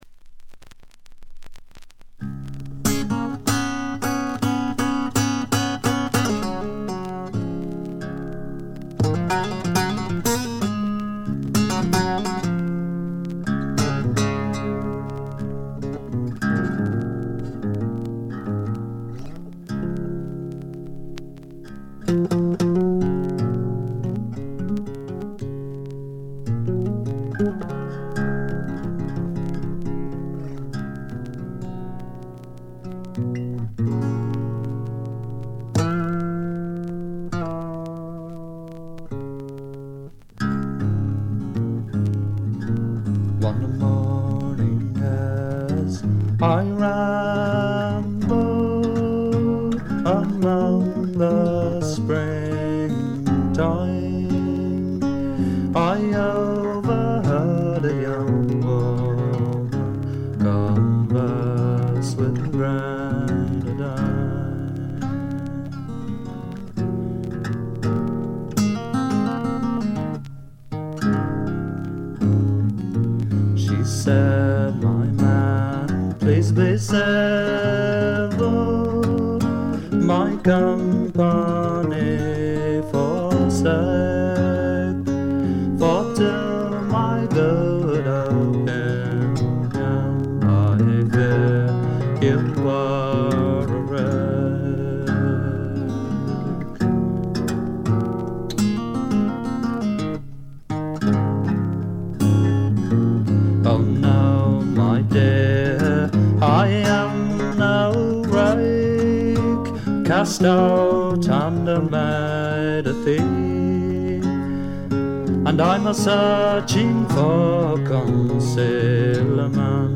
軽微なバックグラウンドノイズ、チリプチ少し。
ゲストミュージシャンは一切使わずに、自作とトラッドを味のあるヴォーカルと素晴らしいギターで表情豊かに聴かせてくれます。
試聴曲は現品からの取り込み音源です。